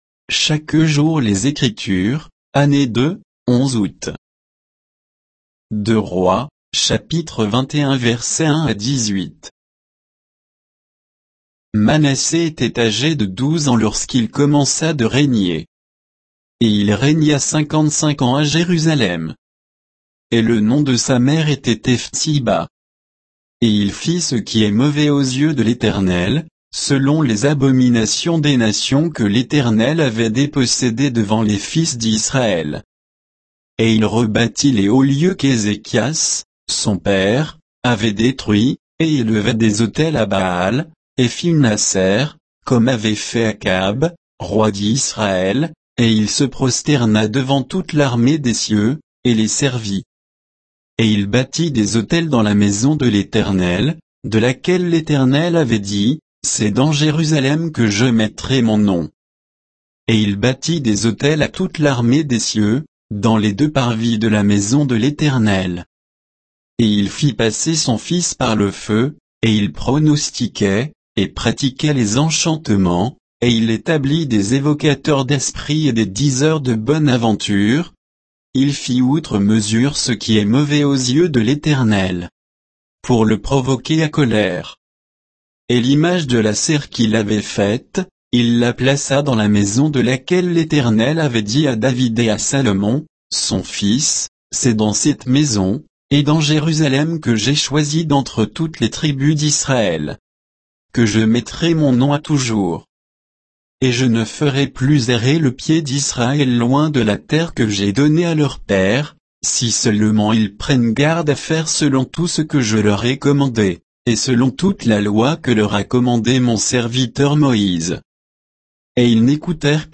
Méditation quoditienne de Chaque jour les Écritures sur 2 Rois 21, 1 à 18